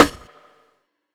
Snares
ACE_RIM.wav